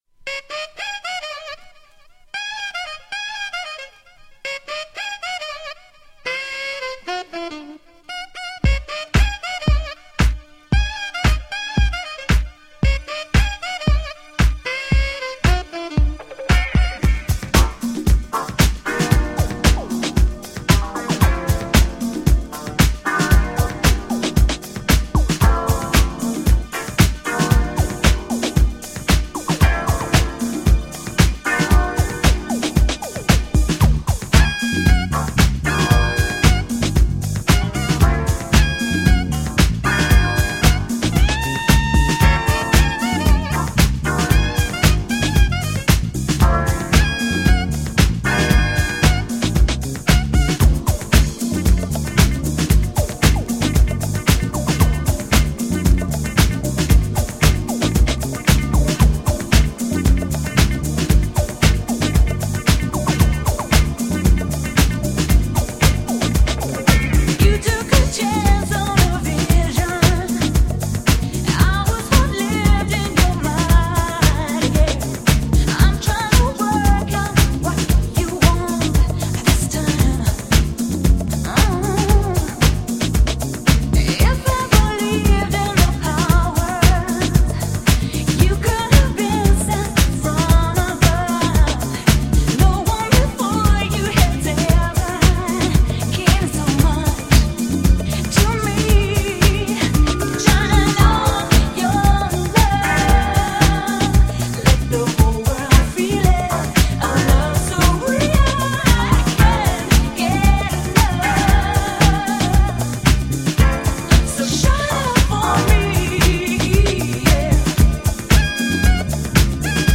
UK美メロR＆Bを代表するようなとてもイイ曲です。
GENRE R&B
BPM 96〜100BPM